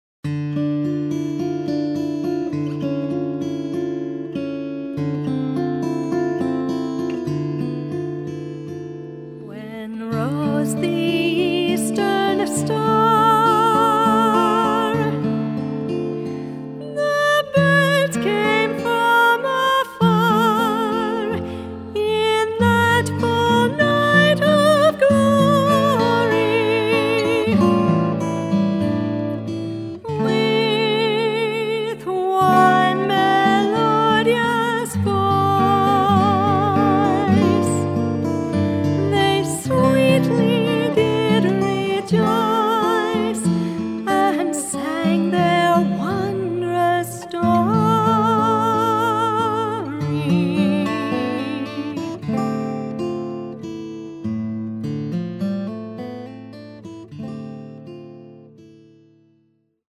Vocals
Acoustic Guitar
Percussion
Violin
Bells, Rhodes Piano